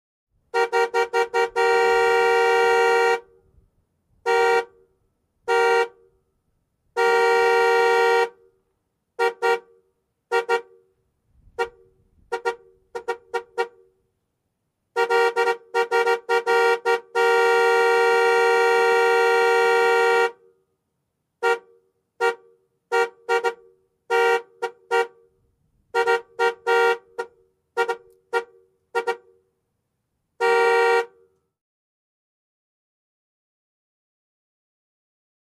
High Two-Tone | Sneak On The Lot
Horn Honks; Jeep Cherokee, Various Lengths.